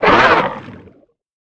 Sfx_creature_pinnacarid_ridehurt_03.ogg